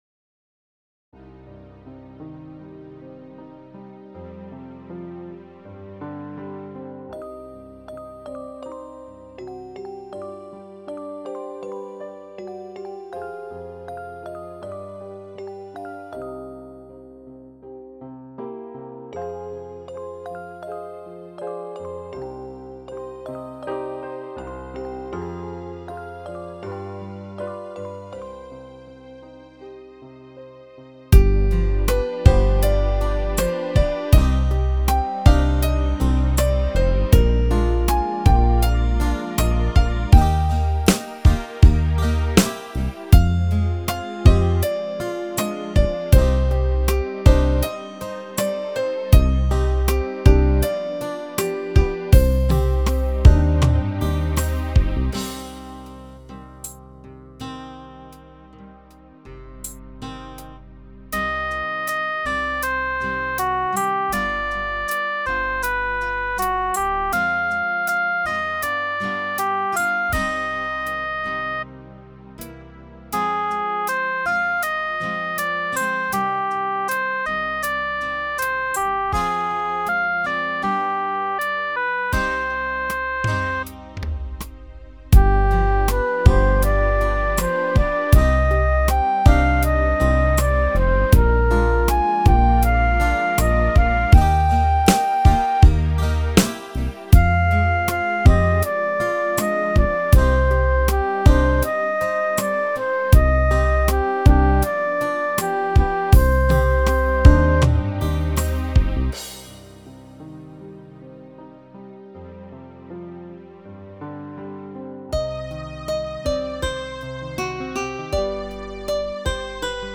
Як ніжно і мелодійно!!
Дуже мелодійно!!!